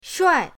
shuai4.mp3